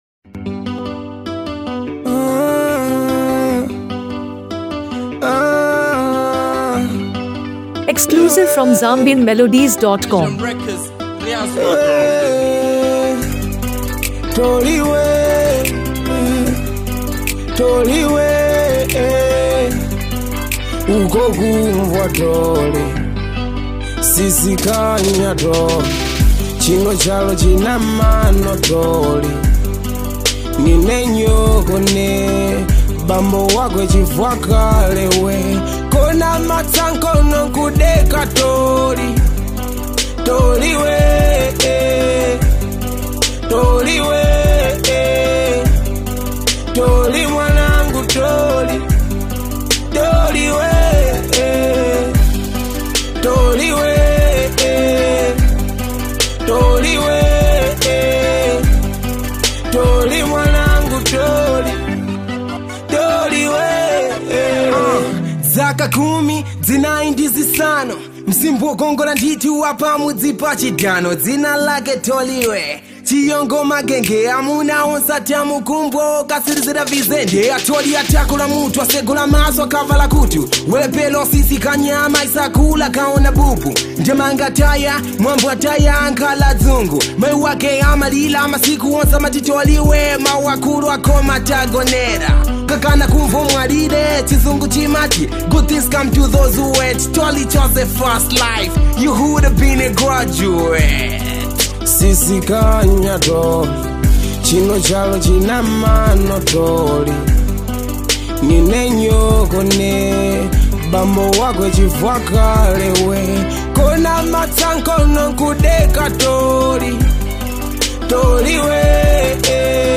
hip-hop and Afro-fusion